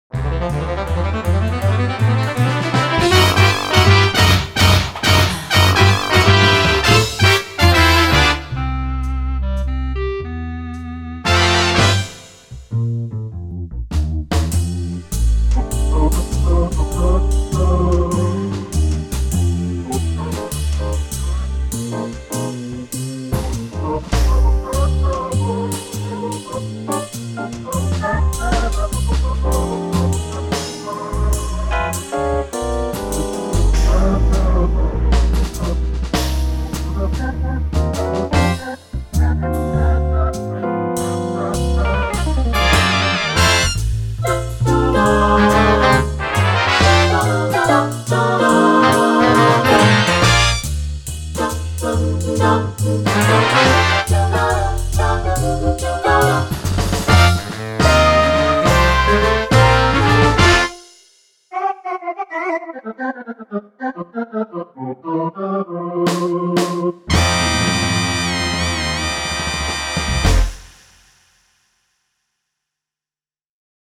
Acoustic Modeling with SX-WSA1R
technics_sx-wsa1r_-_factory_demo_-_qsdisk_jazz_mix.mp3